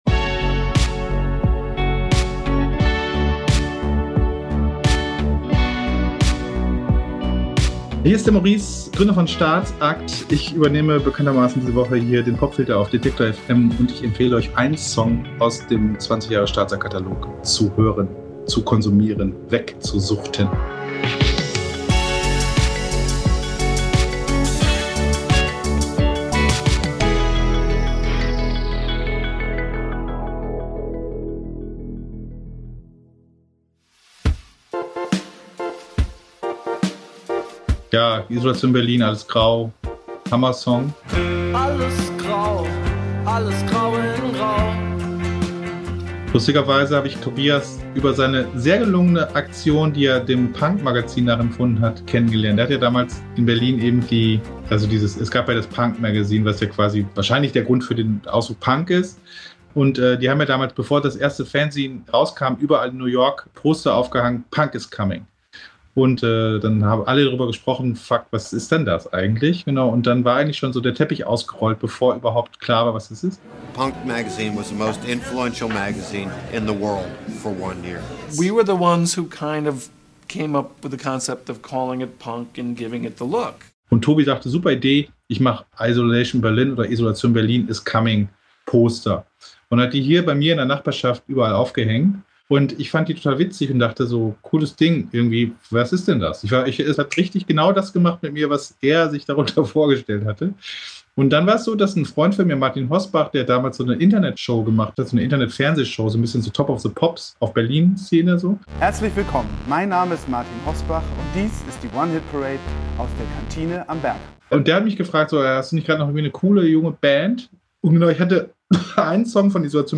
Isolation Berlin singen in „Alles grau“ von der totalen Tristesse und schunkeln konträr dazu im fröhlichen Orgel-Off-Beat.